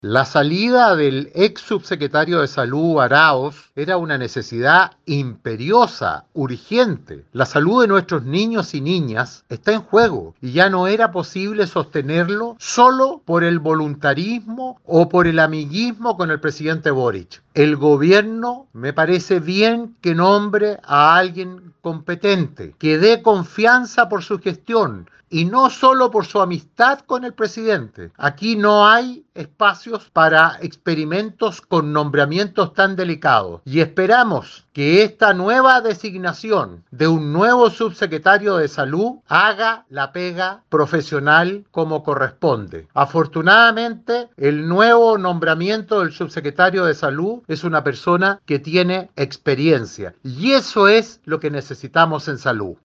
Al respecto el Senador Iván Moreira, indicó que, la salida del ex subsecretario era una necesidad imperiosa: